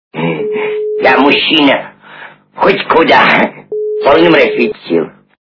» Звуки » Из фильмов и телепередач » Я мужчина, хоть куда - в полном расцвете сил
При прослушивании Я мужчина, хоть куда - в полном расцвете сил качество понижено и присутствуют гудки.